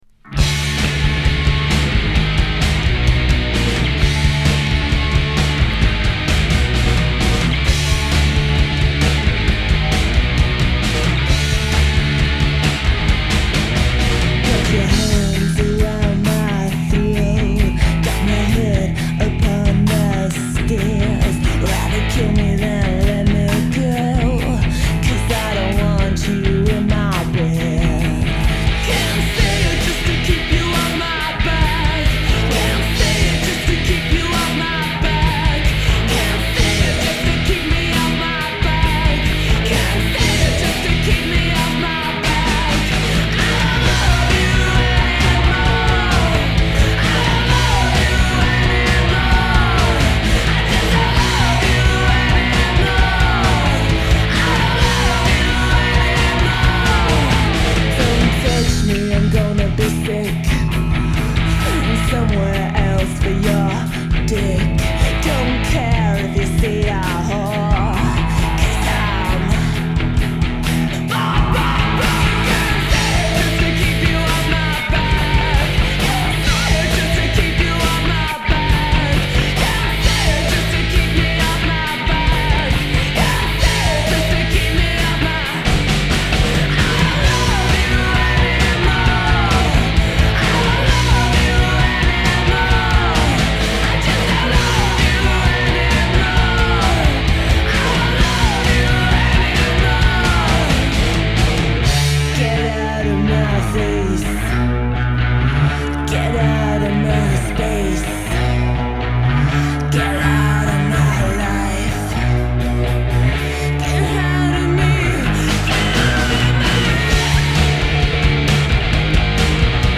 guitar/vocals
bass
drums
guitar